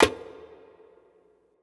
Perc  (6).wav